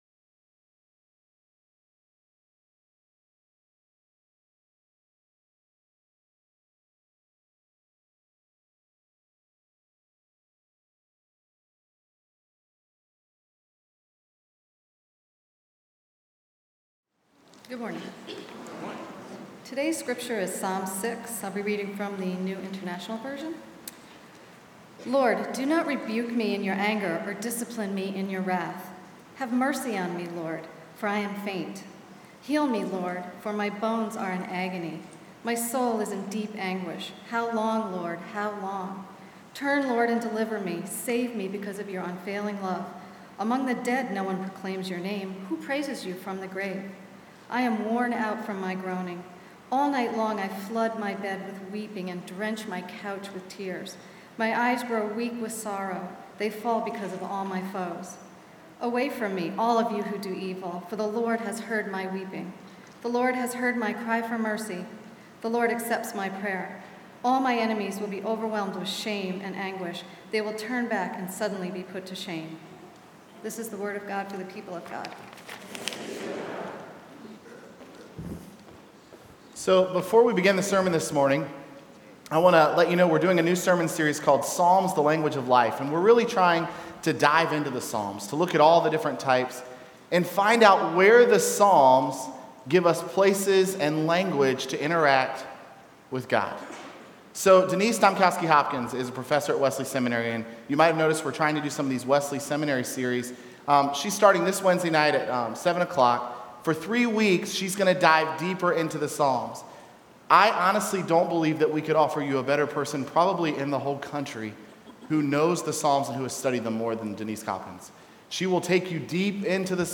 sermon11-4-12.mp3